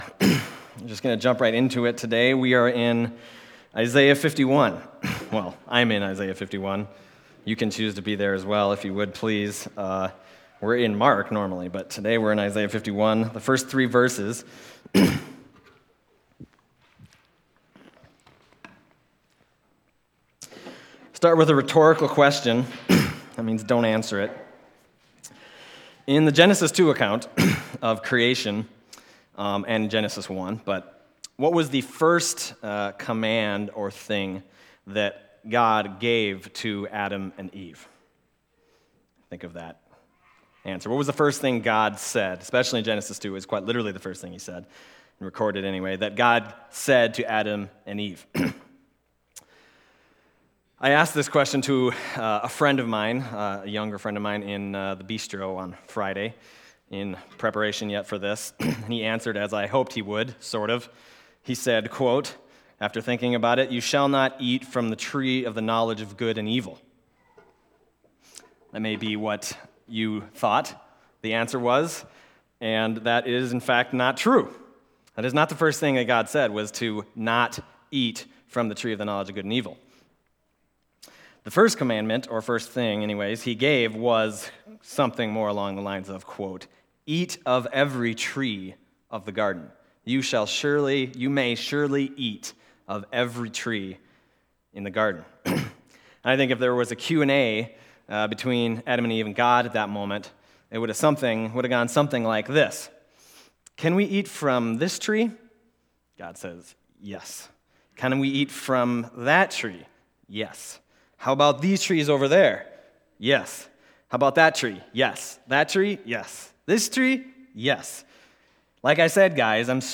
Other Passage: Isaiah 51:1-3 Service Type: Sunday Morning Isaiah 51:1-3 « They Shall See the Glory of the Lord Having Eyes Do You Not See?